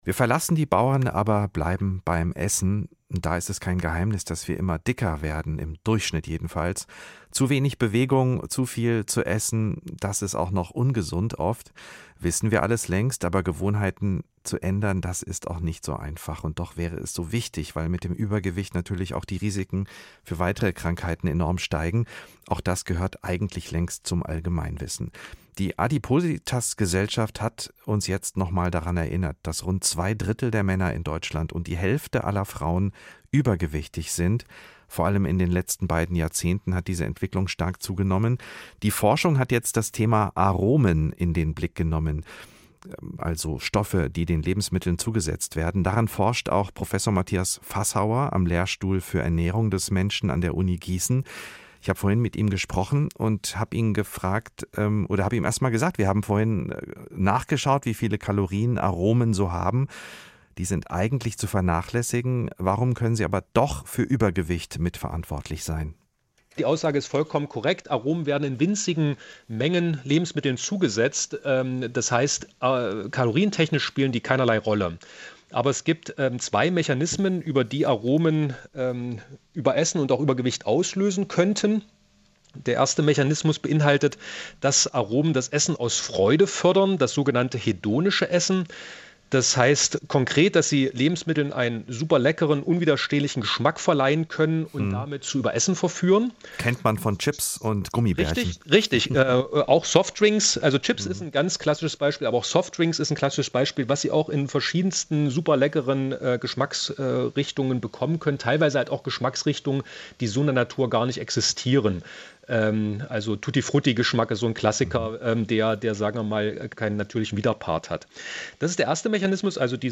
Name: hr-iNFO-Interview; Copyright by hr-iNFO
hr-iNFO-Interview.mp3